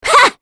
Aselica-Vox_Attack2_kr.wav